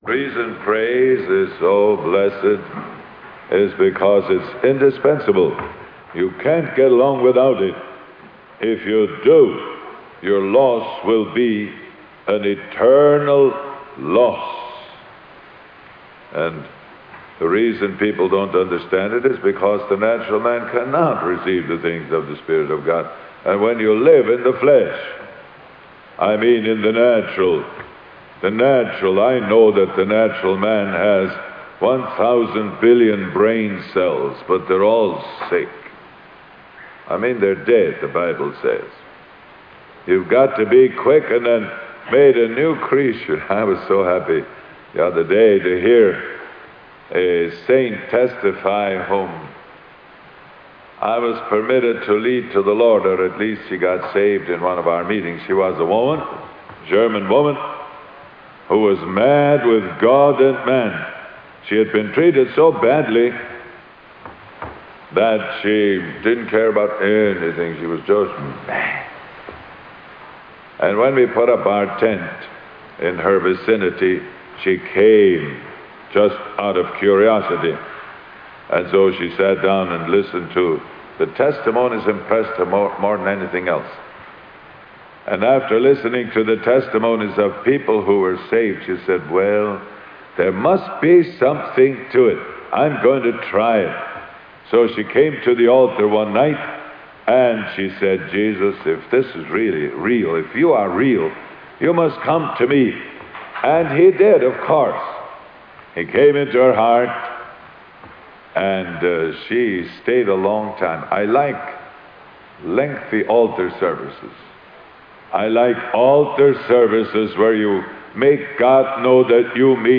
In this sermon, the preacher emphasizes the importance of persistent prayer and not giving up. He shares a story of a woman who had a transformative experience with God during an altar service.